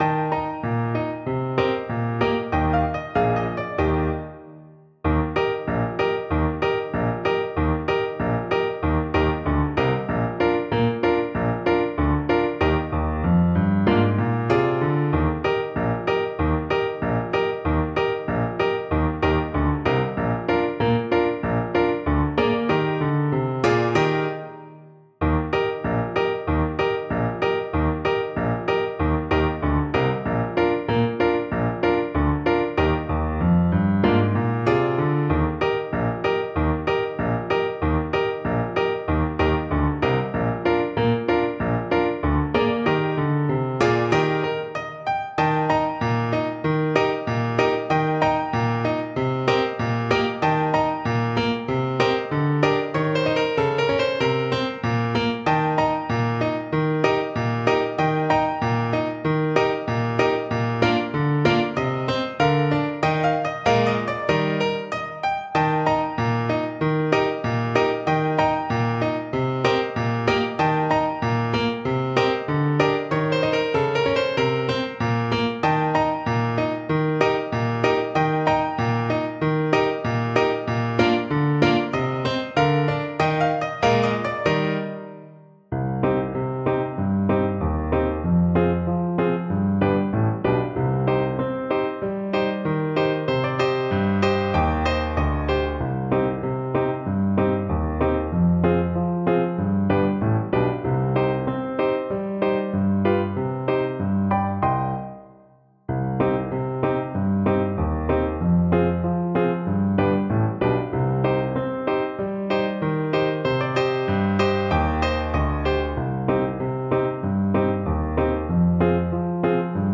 Trombone
Eb major (Sounding Pitch) (View more Eb major Music for Trombone )
Allegro =c.140 (View more music marked Allegro)
2/4 (View more 2/4 Music)
Traditional (View more Traditional Trombone Music)